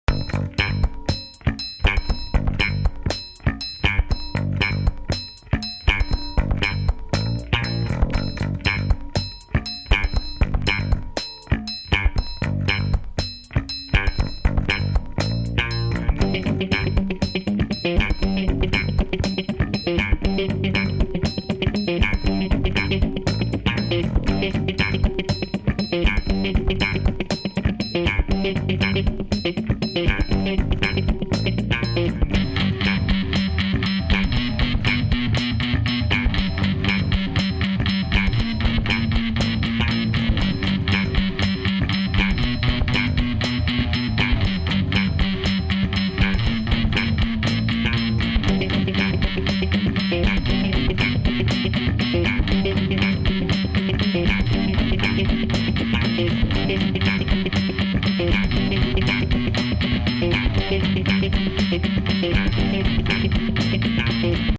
home of the daily improvised booty and machines -
3 bass grooves